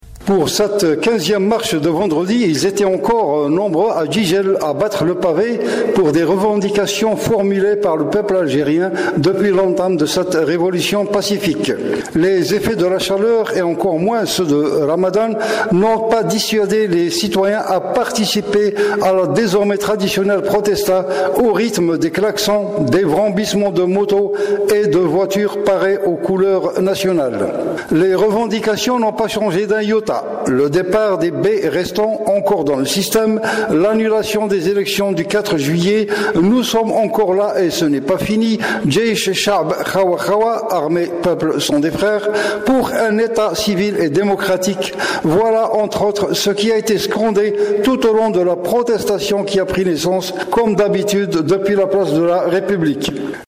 Compte rendu